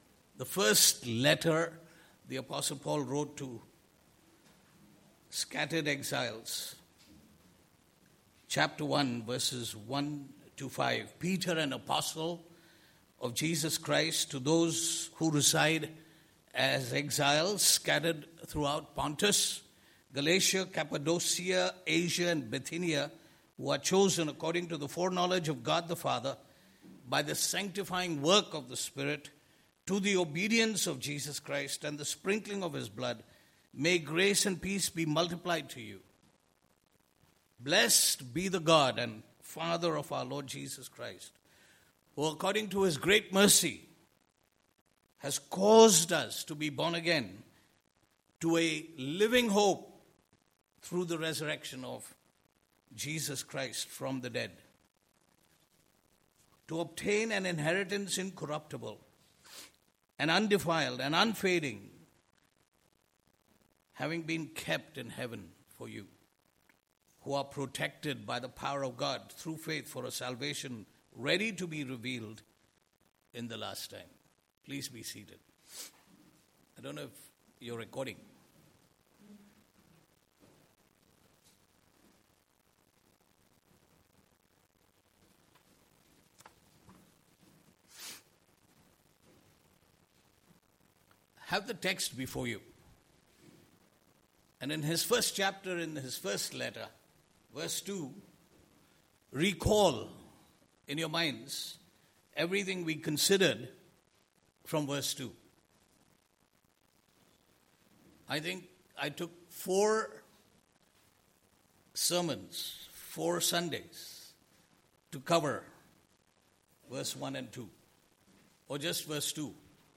Passage: 1 Peter 1:3-5 Service Type: Sunday Morning